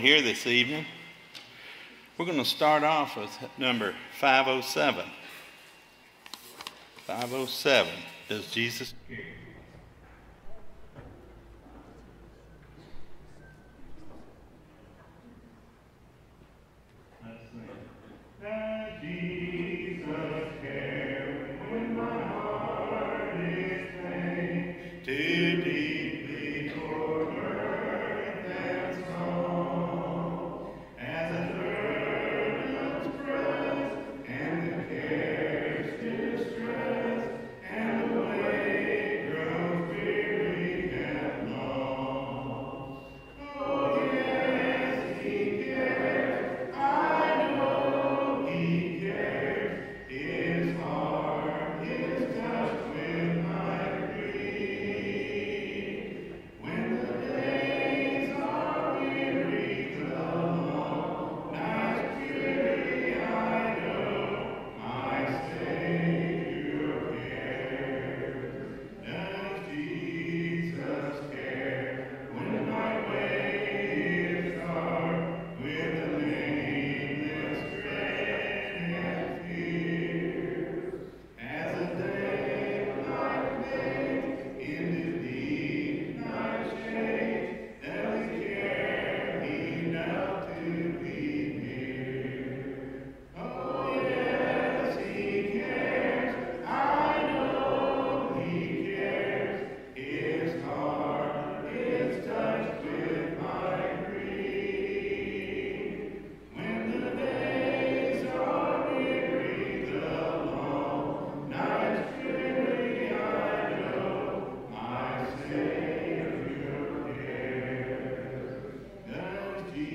Ephesians 5:25-27, English Standard Version Series: Sunday PM Service